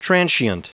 Prononciation du mot transient en anglais (fichier audio)
Prononciation du mot : transient